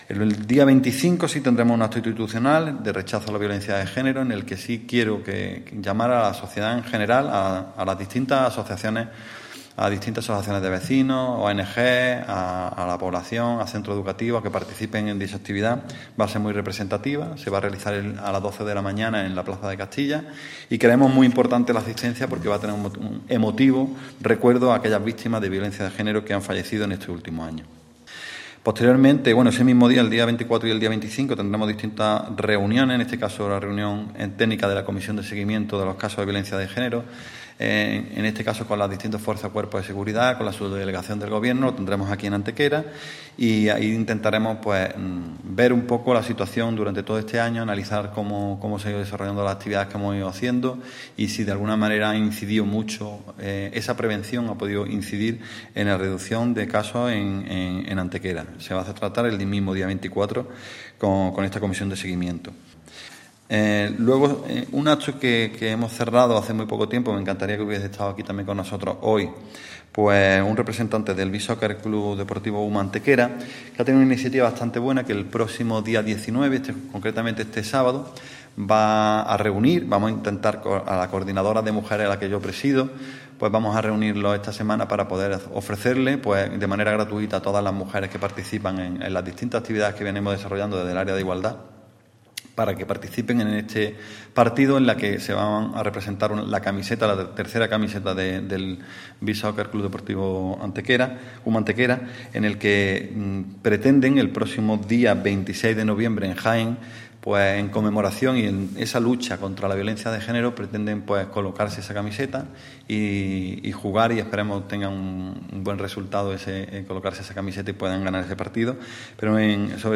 El teniente de alcalde delegado de Programas Sociales, Igualdad, Cooperación Ciudadana y Vivienda, Alberto Arana, ha informado en la mañana de hoy en rueda de prensa de las actividades que en nuestra ciudad conmemoran el Día Internacional de la Eliminación de la Violencia contra la Mujer en torno al próximo viernes 25 de noviembre, fecha establecida por la ONU como recordatorio de las hermanas Mirabal, tres activistas políticas que fueron brutalmente asesinadas en 1960 por orden del gobernante dominicano Rafael Trujillo.
Cortes de voz